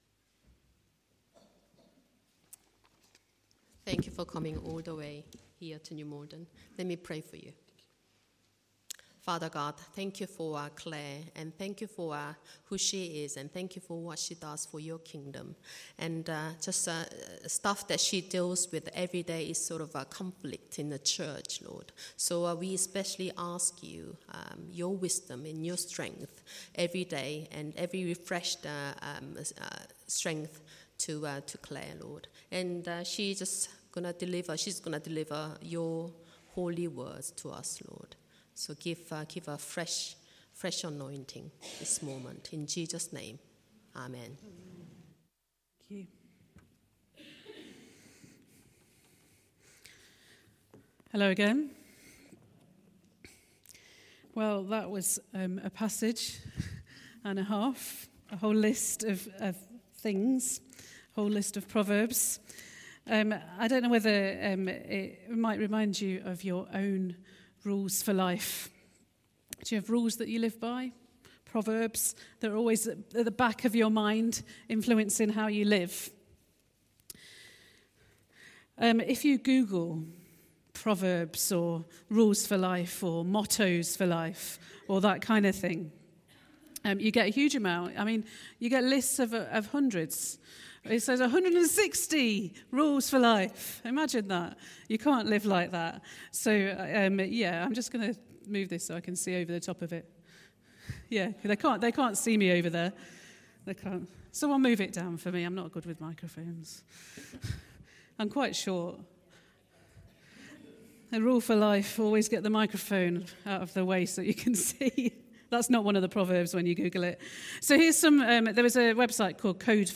Sunday Service
Theme: A Life to Pursue Sermon